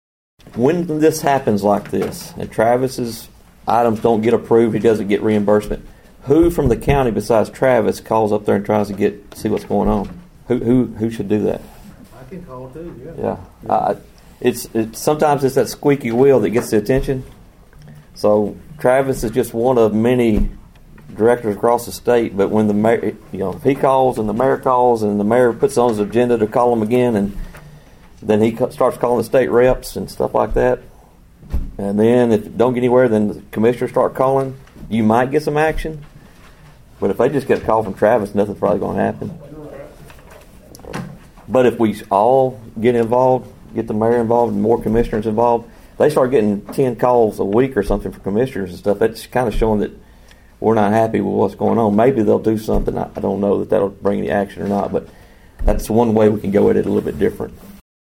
The lack of timely funding to the Obion County Recycling Center drew sharp criticism at Friday’s County Commission meeting.
Budget Committee Chairman Sam Sinclair Jr. explained an approach that could help hasten the distribution of the needed funds.(AUDIO)